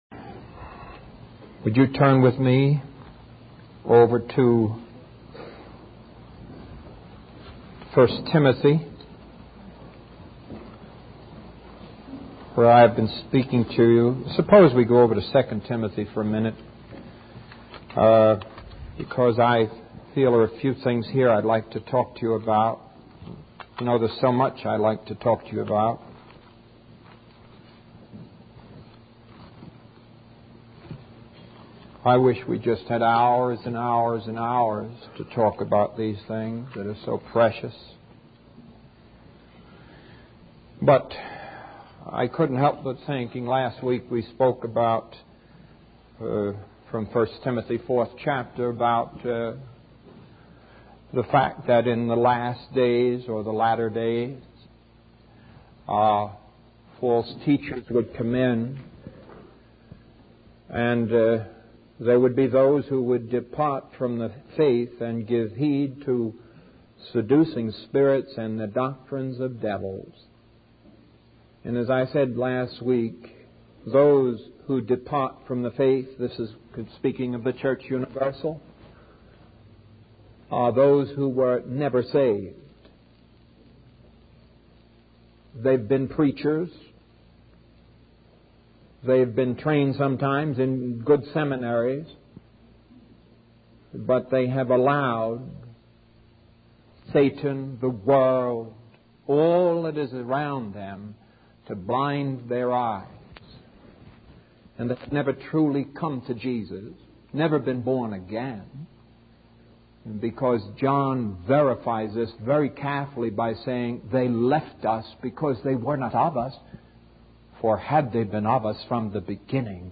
In this sermon, the speaker emphasizes the importance of recognizing the truth of God's Word.